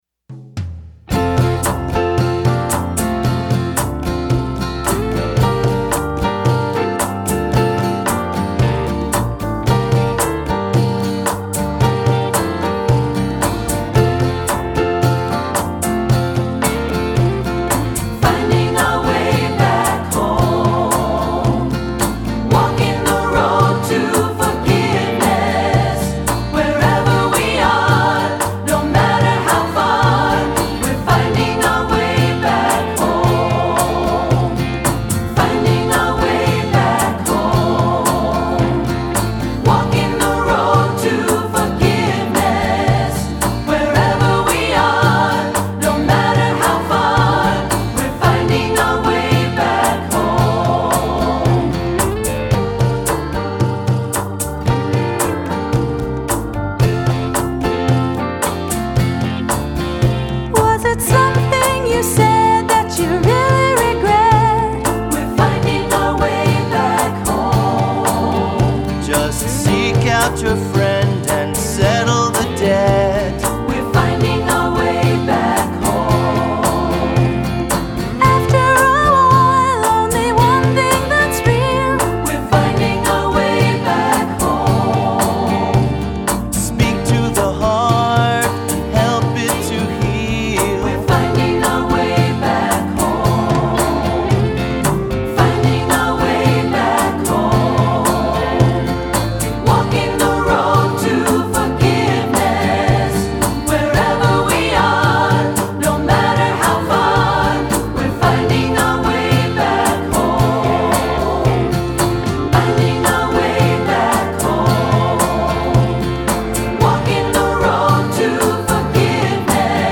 Voicing: 3-Part